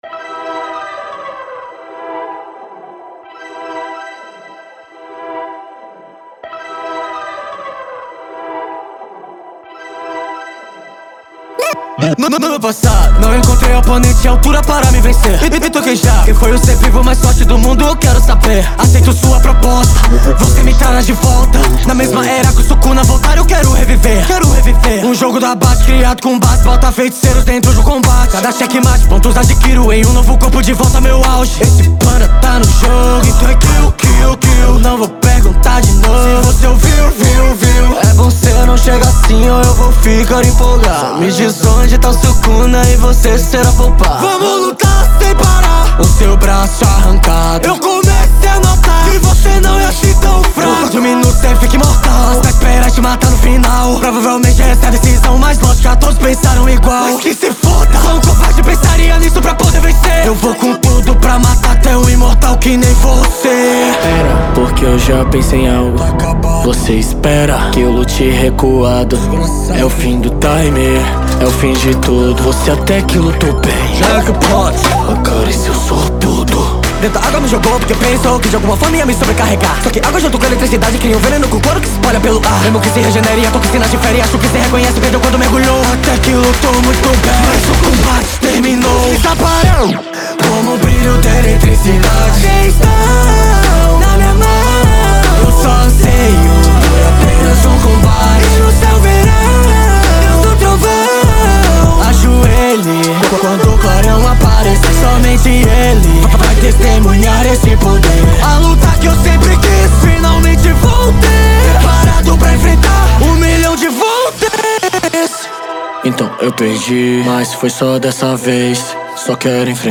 2025-02-23 14:56:12 Gênero: Rap Views